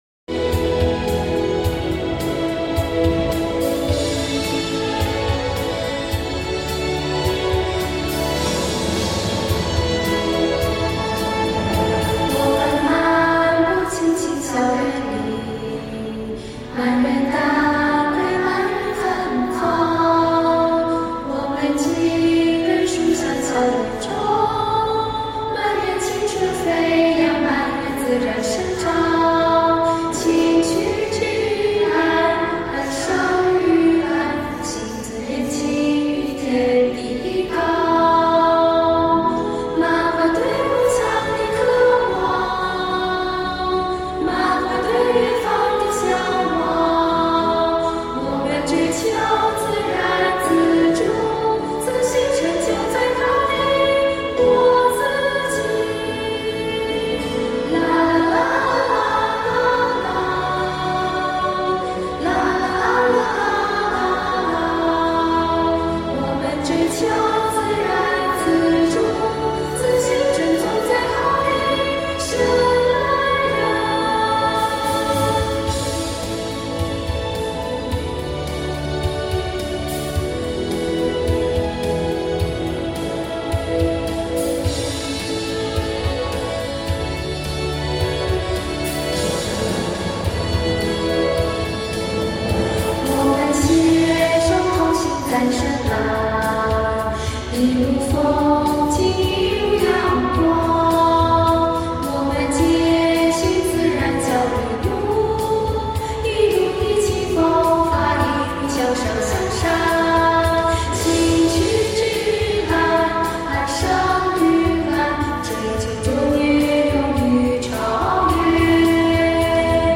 校歌（现场版）(2).mp3